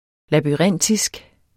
Udtale [ labyˈʁεnˀdisg ]